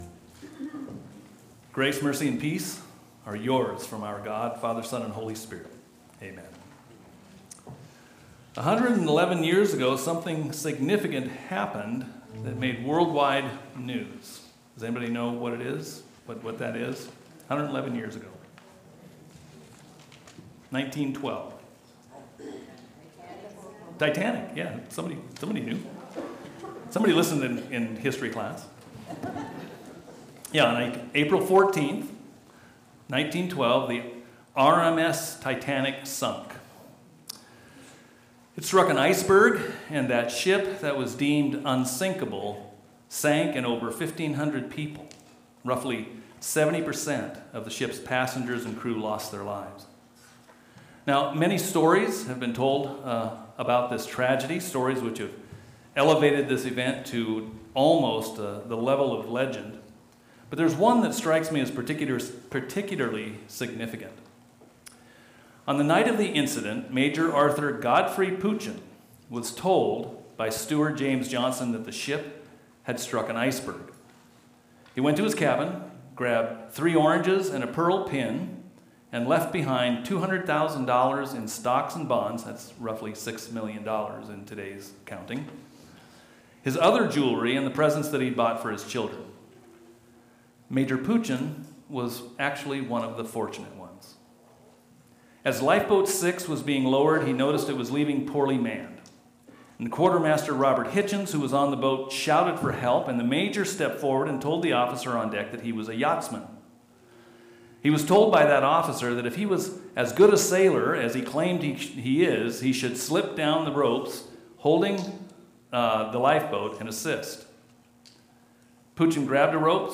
Sunday Service Sermons